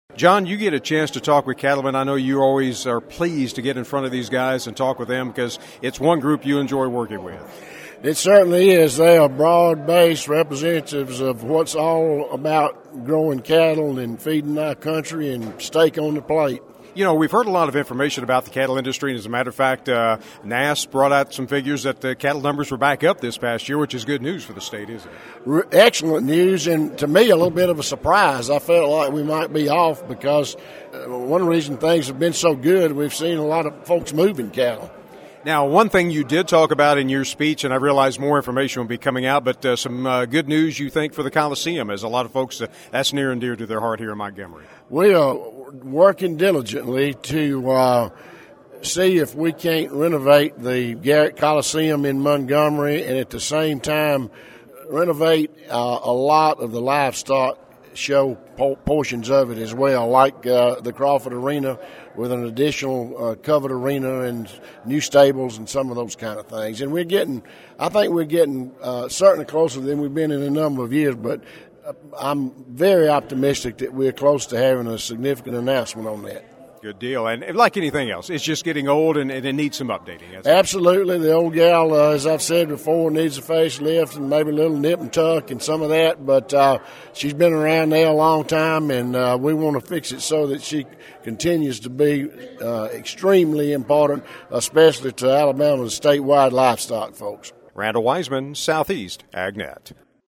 Alabama Ag Commissioner Speaks to Cattlemen - Southeast AgNET
During this morning’s ACA board meeting, one of the speakers was Alabama’s Commissioner of Agriculture John McMillan who updated cattlemen about some of the happenings with his office in Montgomery.